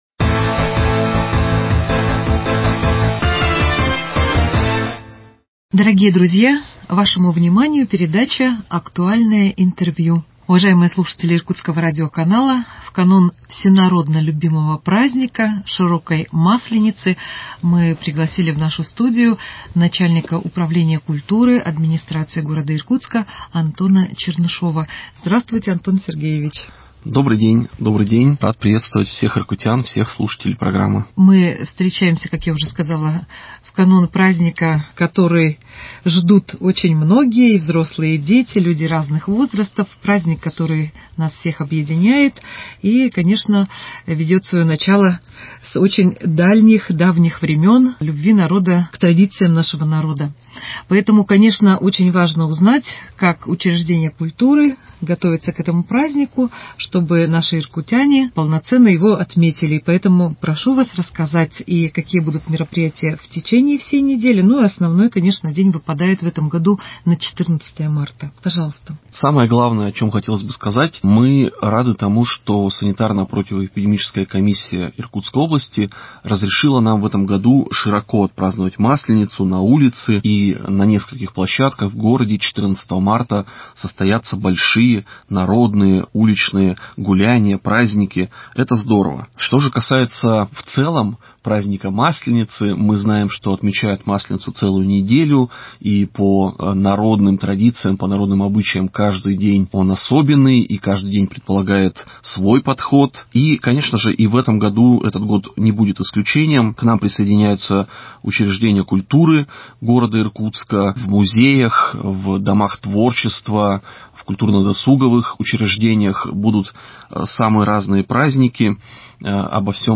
Актуальное интервью: Мероприятия на Масленицу 05.03.2021